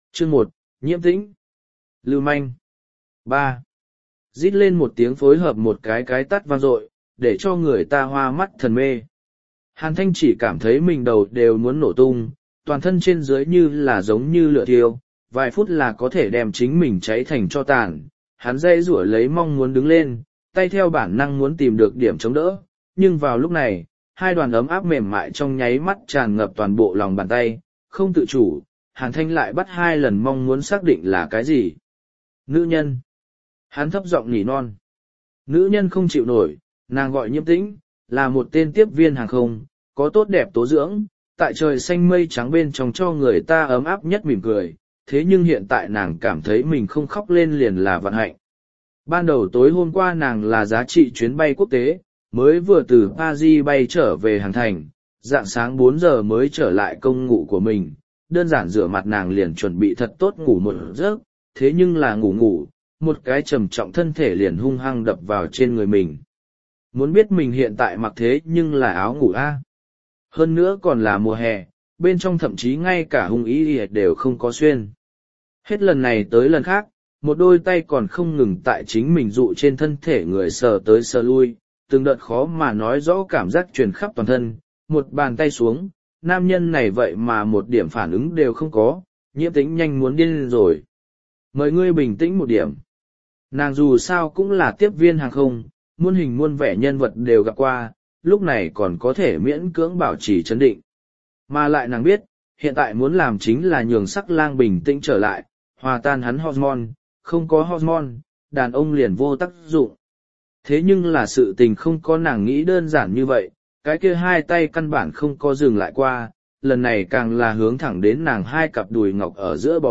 Đô Thị Chi Tiên Tôn Trọng Sinh Audio - Nghe đọc Truyện Audio Online Hay Trên TH AUDIO TRUYỆN FULL